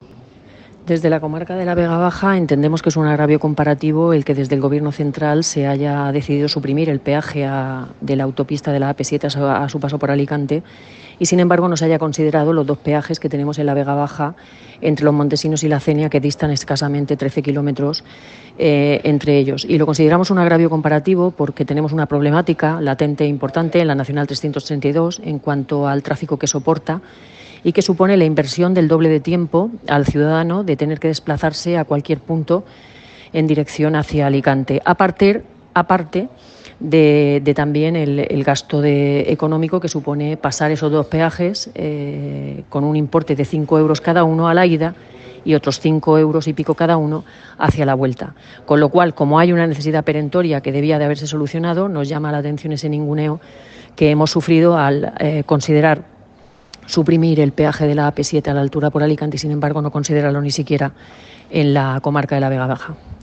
Corte-de-Voz-Marina-Saez.m4a